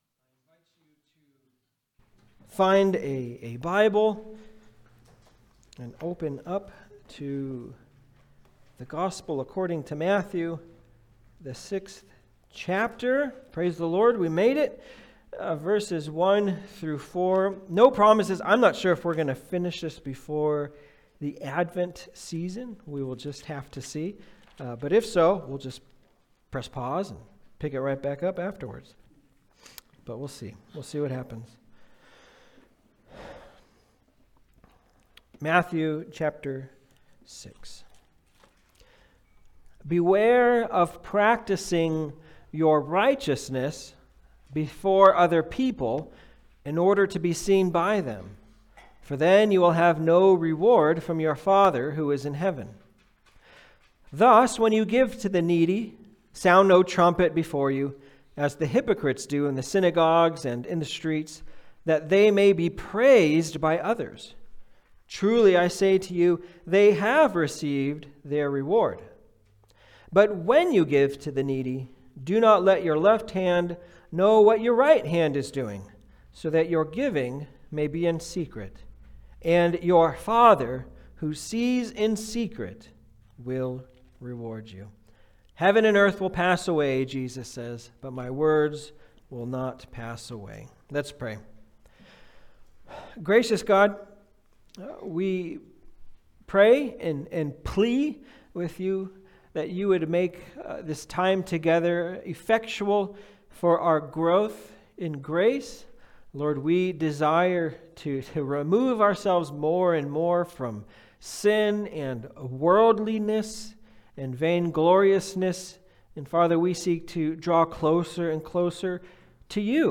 Passage: Matthew 6:1-4 Service Type: Sunday Service